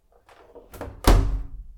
縦型洗濯機閉める
cl_washer.mp3